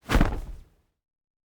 AirDash_01.ogg